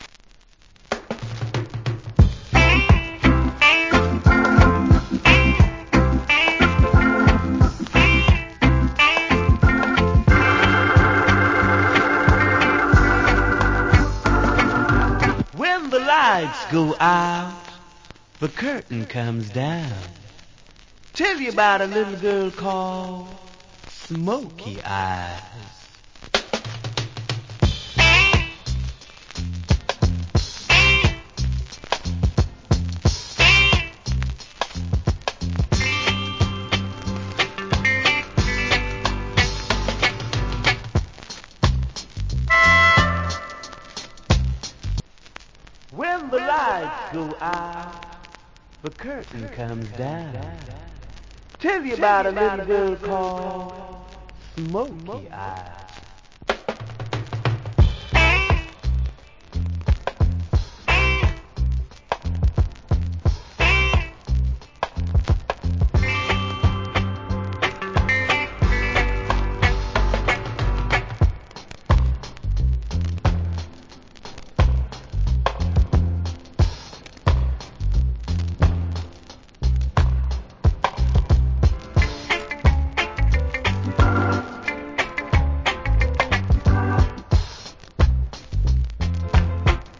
Nice Melodica Reggae Inst.